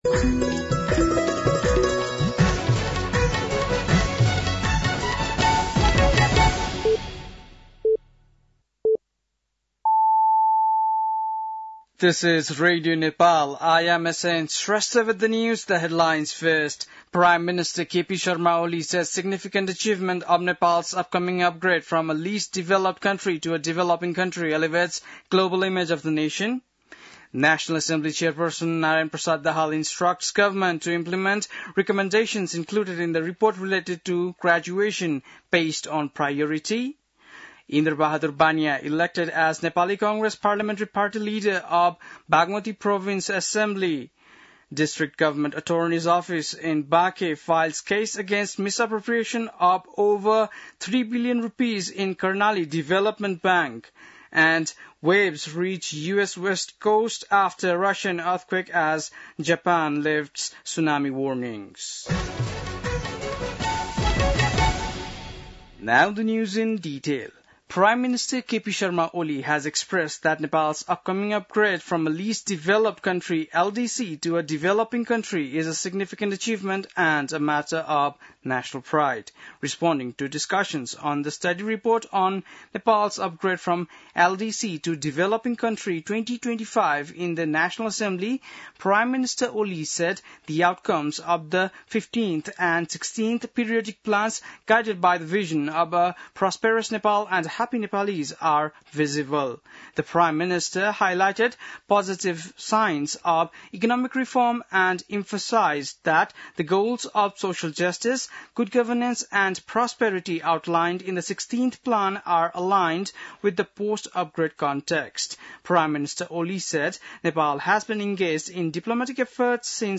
बेलुकी ८ बजेको अङ्ग्रेजी समाचार : १४ साउन , २०८२
8-pm-english-news-4-14.mp3